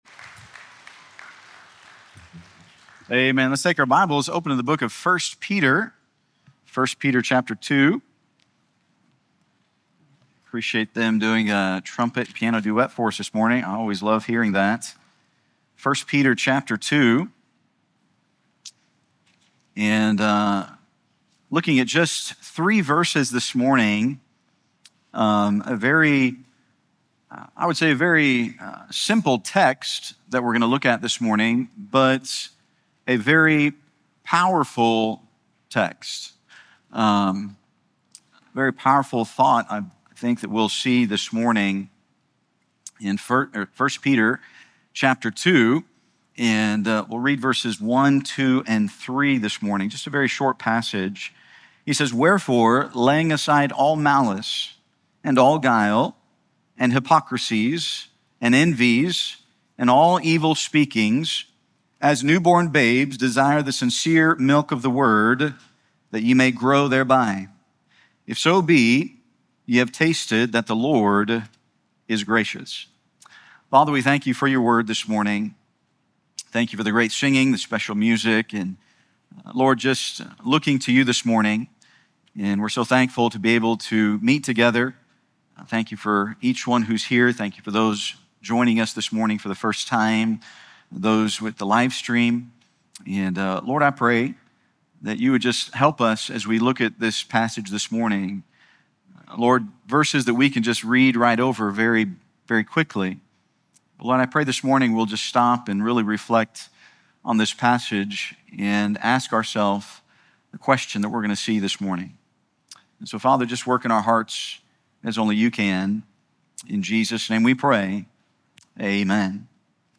This sermon from 1 Peter 2:1-3 asks a simple but searching question: are you growing as a Christian?